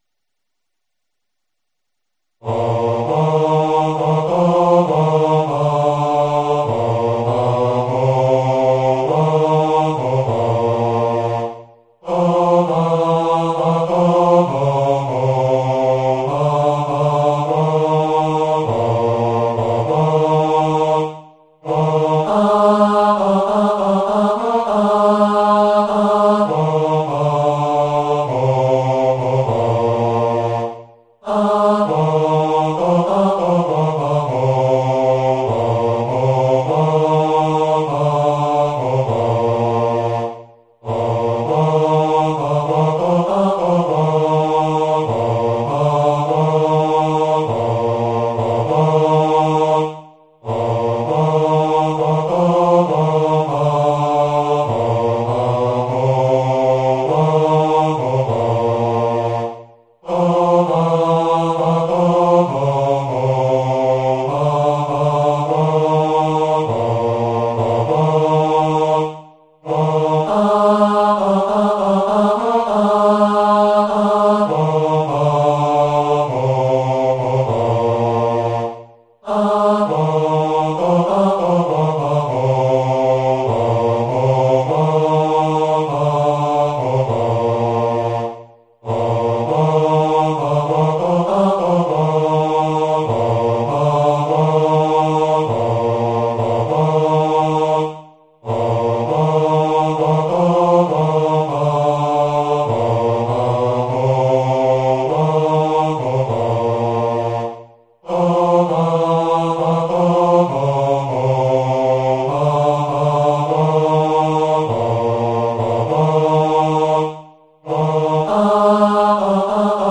荒牧中校歌 バス (音声ファイル: 956.8KB)